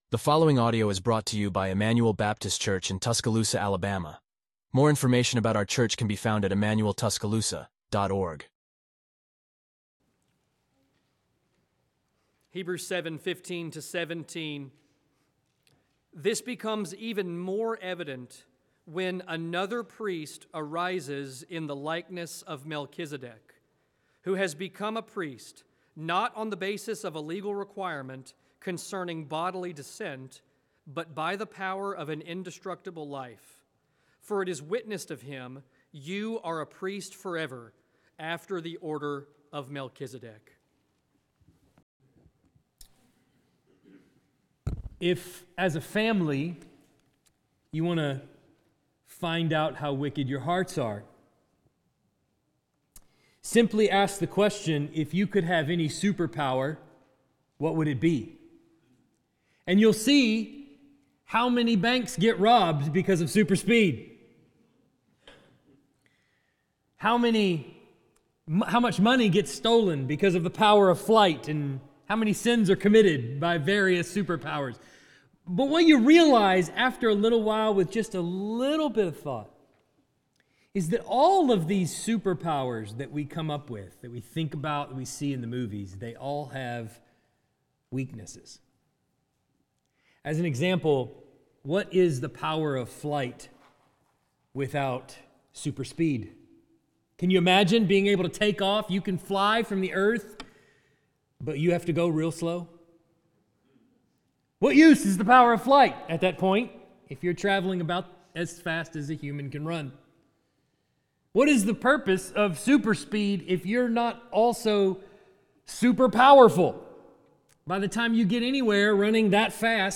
Expository Sermons from Emmanuel Baptist Church in Tuscaloosa, Alabama